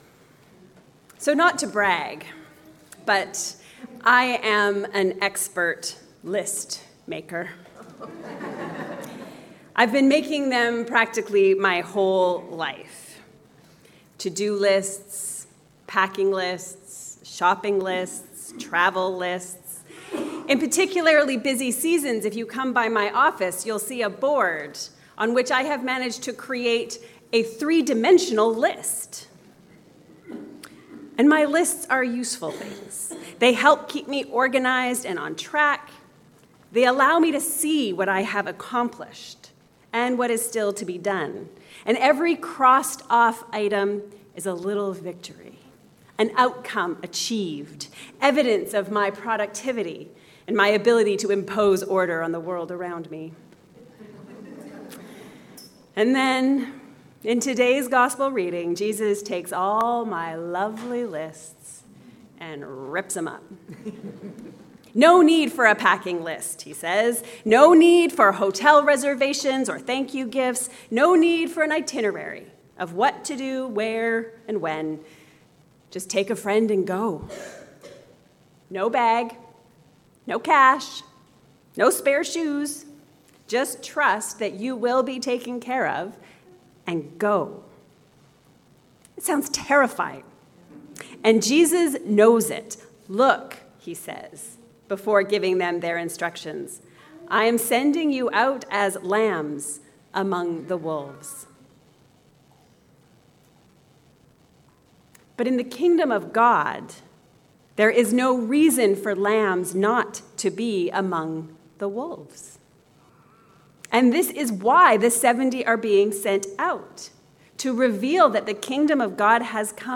Freed from failure…and from success. A sermon on Luke 10